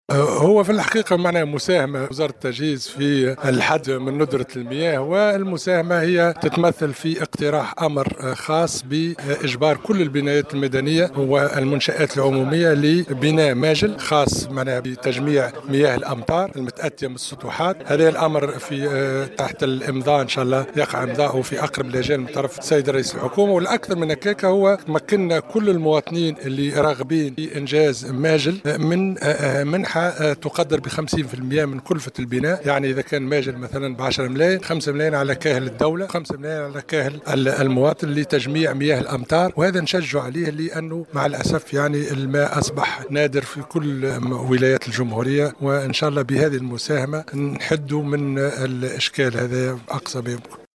وأكد العرفاوي، في تصريح لمراسل الجوهرة أف أم، لدى زيارته اليوم السبت، لسد "وادي الكبير" بولاية جندوبة، مرفوقا بوزير الفلاحة والموارد المائية والصيد البحري، سمير بالطيب، أكد أن هذا الأمر سيتم إمضاؤه في أقرب الآجال من طرف رئيس الحكومة.